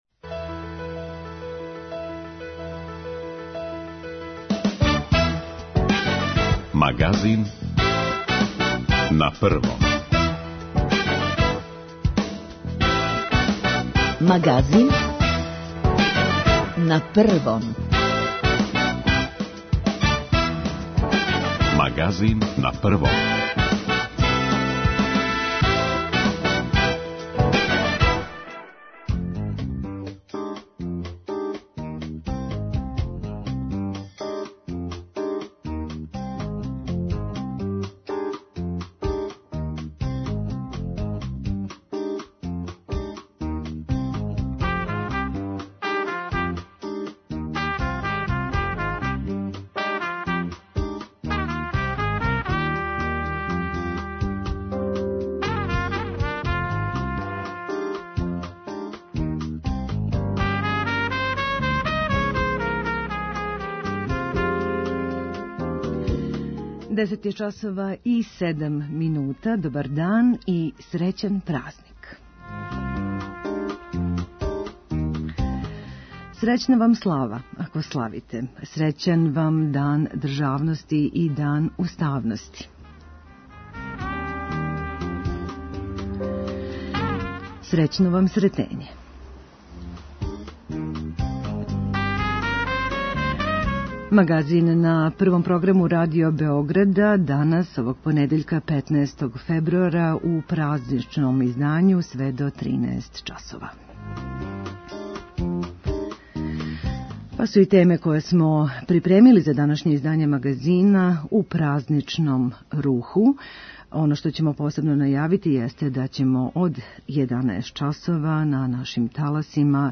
Србија данас, на Сретење, прославља Дан државности у спомен на два велика историјска догађаја која су се догодила управо на тај велики верски празник - подизање Првог српског устанка 1804. и доношење првог устава 1835. године. Репортери Радио Београда пратиће свечаности и у Марићевића јарузи и у Палати Србија, иако ће део програма прославе бити он-лајн.